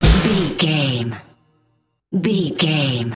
Epic / Action
Aeolian/Minor
Fast
drum machine
synthesiser
strings